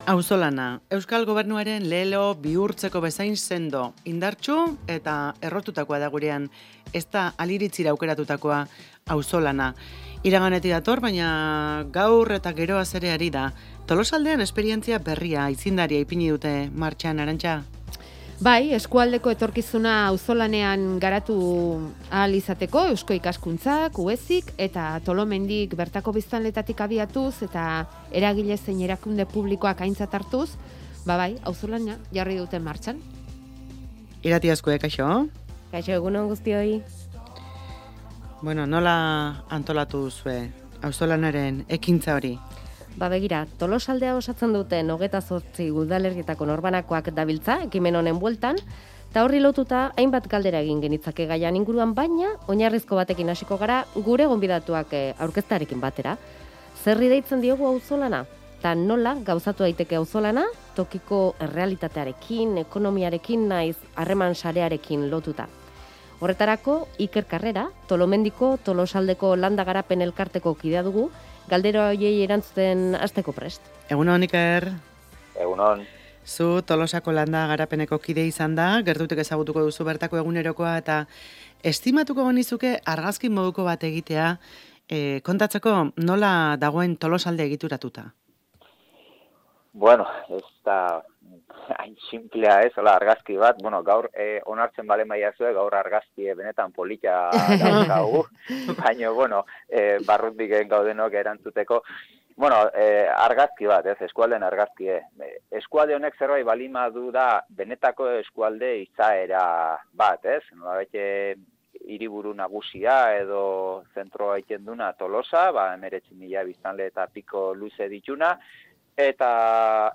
Proiektuaren berri emateko eta auzolanaren inguruan solasteko hiru kidek parte hartuko dute irratsaioan